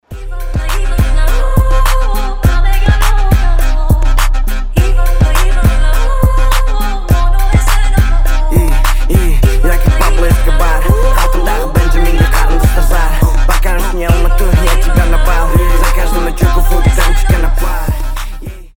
• Качество: 320, Stereo
восточные
Казахский рэп